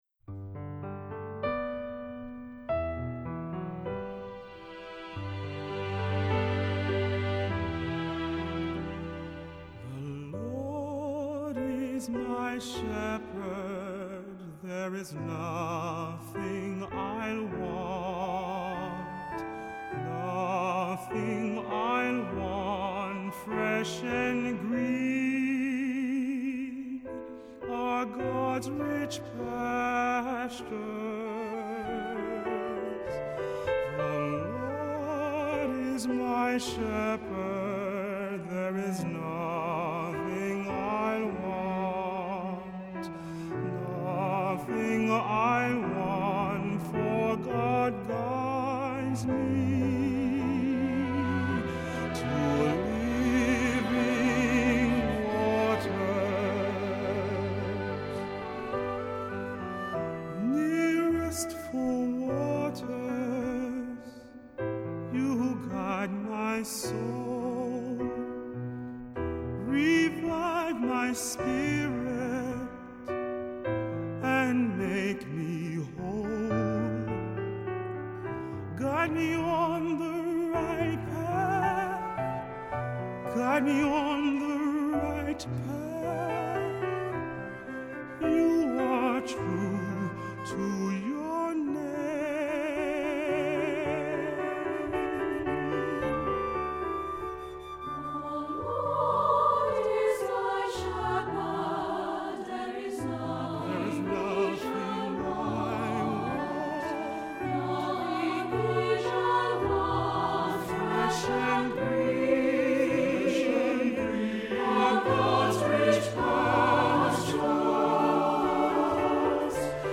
Accompaniment:      Keyboard, Flute
Music Category:      Christian